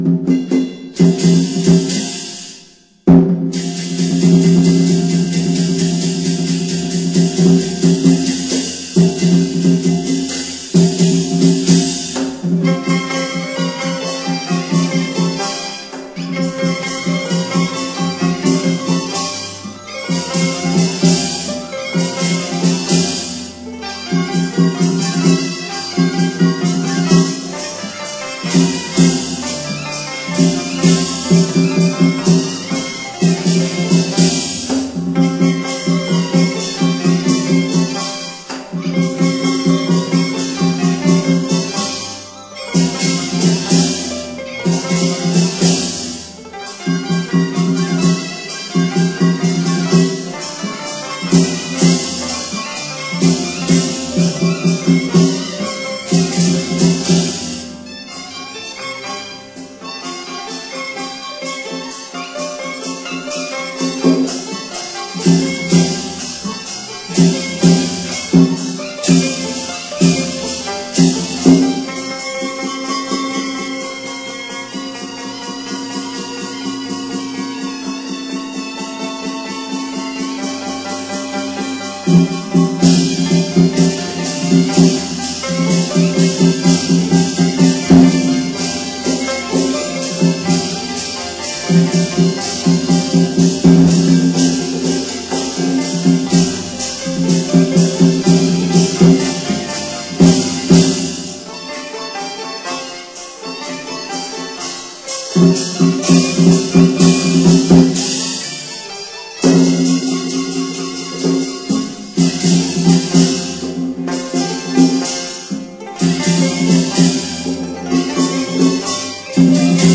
潮州音乐简介
潮州音乐主要特点是古朴典雅、优美抒情。